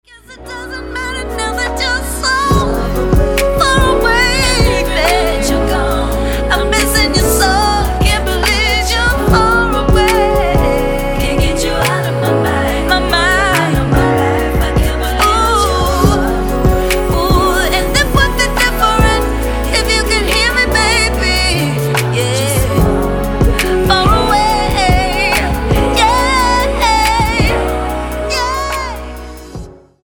W/ Vocals